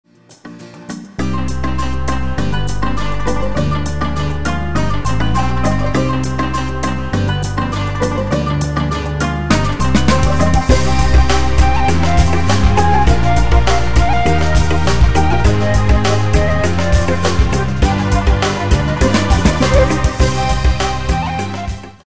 Folk music- instrumental music